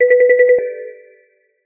SMSに合うマリンバの音色の通知音。